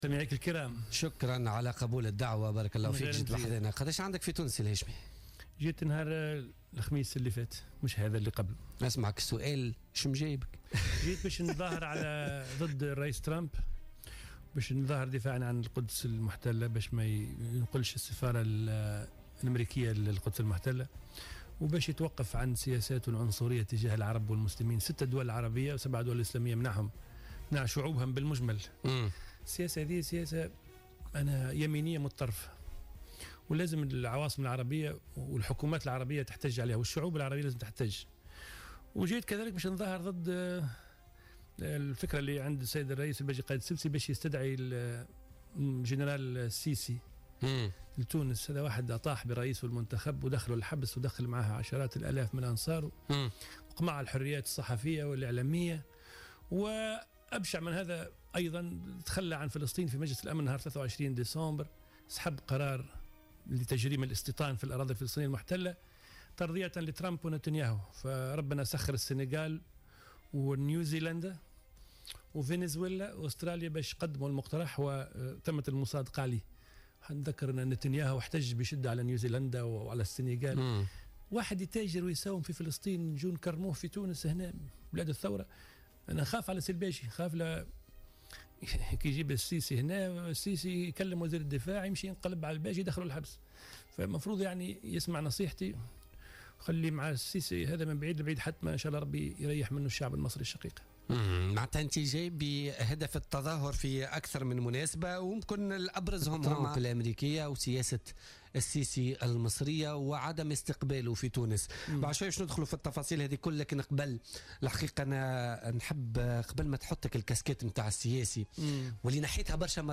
أكد رئيس تيار المحبة الهاشمي الحامدي ضيف بوليتيكا اليوم الاثنين أنه جاء إلى تونس للتظاهر ضد الرئيس الأمريكي ترامب دفاعا عن القدس المحتلة وحتى يتوقف عن سياساته العنصرية تجاه العرب والمسلمين لأنها سياسة متطرفة ويجب أن تحتج عليها العواصم العربية والحكومات والشعوب حسب قوله.